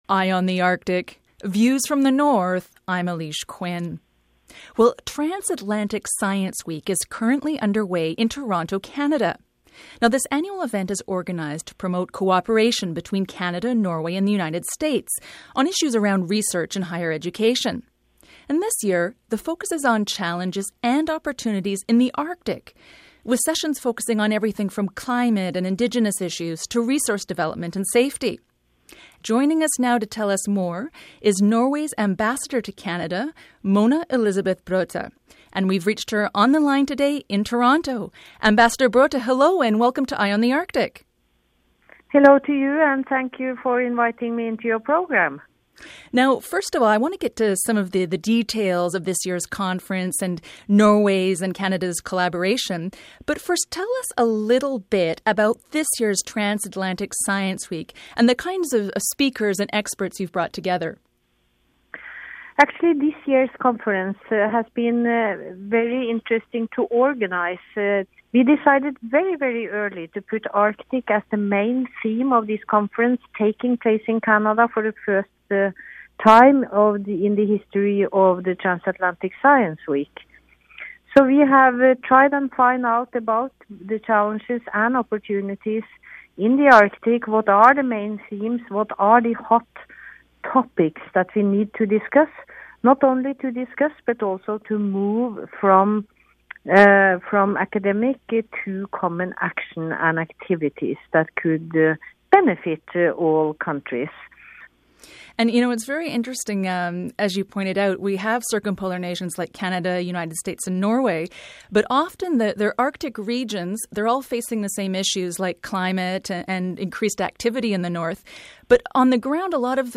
For this week’s Eye on the Arctic feature interview, we spoke with Mona Elisabeth Brøther, Norway’s ambassador to Canada on the importance the conference, Canadian-Norwegian cooperation in the North and why closer circumpolar relations are needed in everything from security to business: